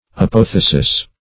Search Result for " apothesis" : The Collaborative International Dictionary of English v.0.48: Apothesis \A*poth"e*sis\, n. [Gr.
apothesis.mp3